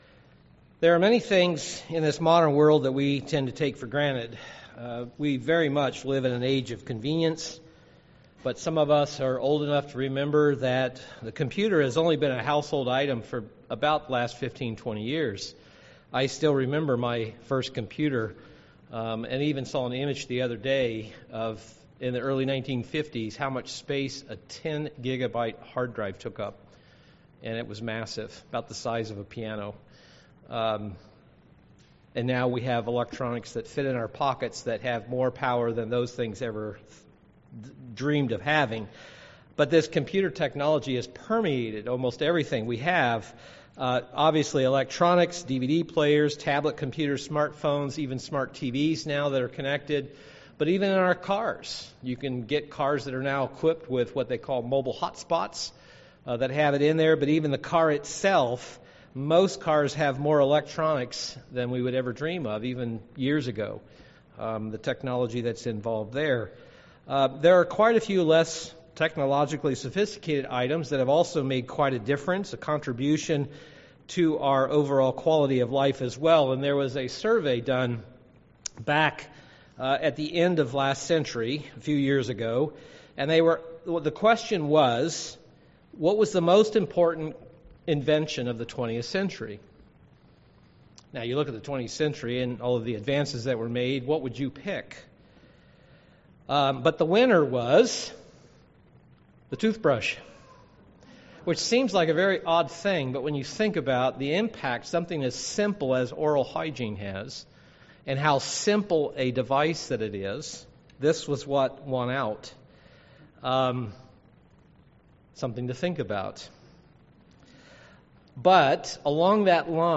Sermons
Given in Milwaukee, WI